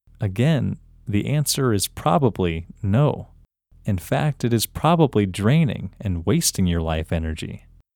OUT – English Male 25